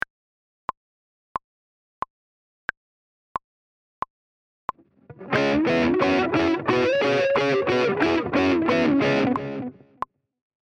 Double Stop Soloing – C#m Pentatonic ‘G’ and ‘B’ Strings
The following example shows the C# Minor Pentatonic scale on just the G and B strings.
Example-11d-wclick.mp3